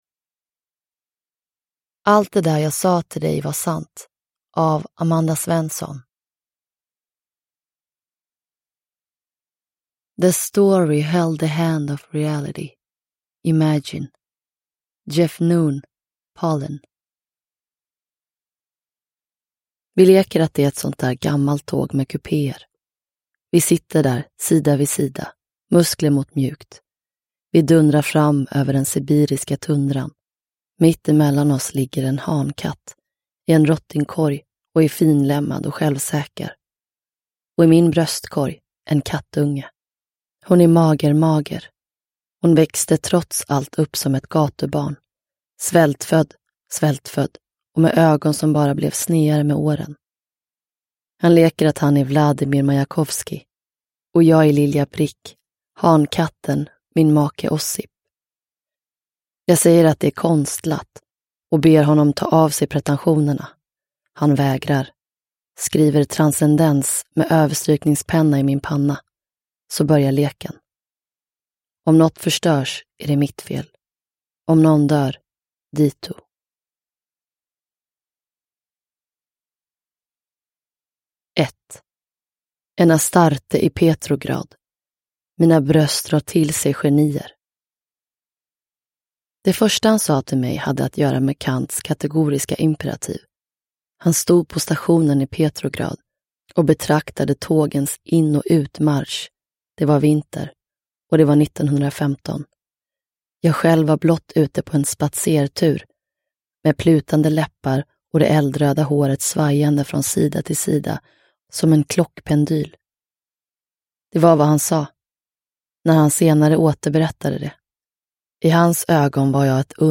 Uppläsare: Gizem Erdogan
• Ljudbok